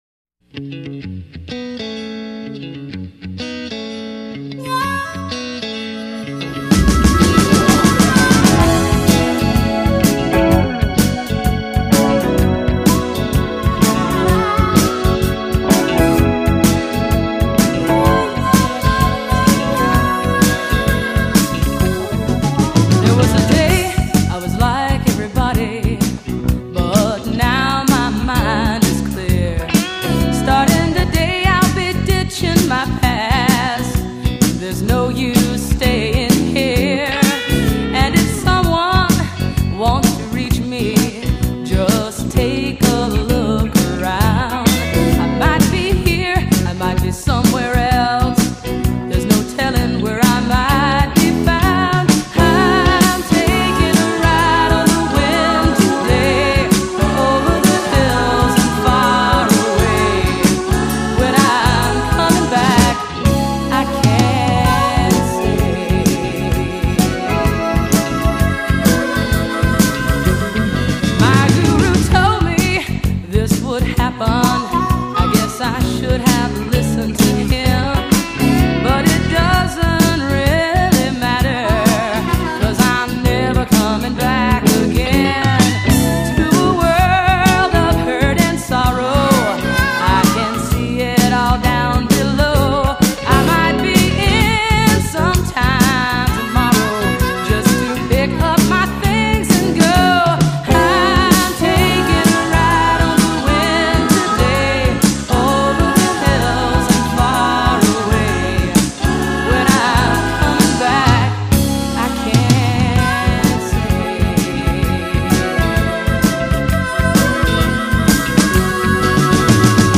vocals, guitar
Drums
Harmonica
Guitar, keyboards